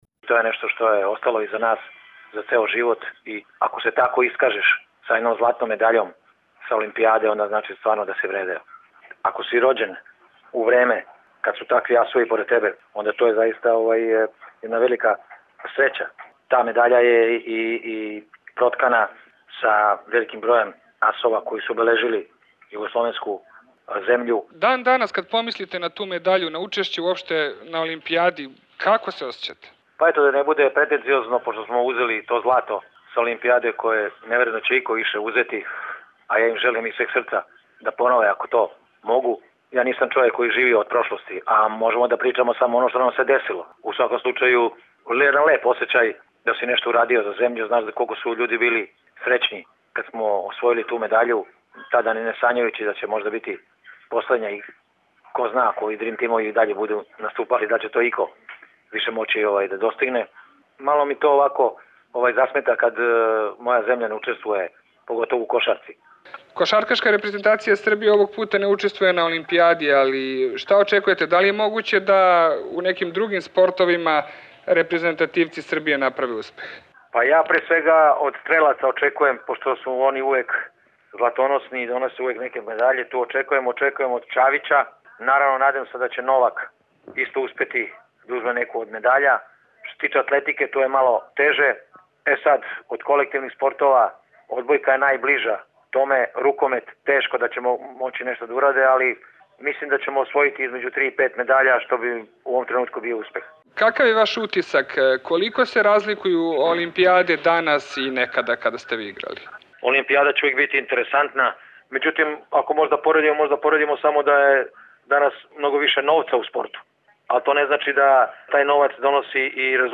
Razgovor sa Mokom Slavnićem: Biti olimpijski šampion je vrhunac u sportu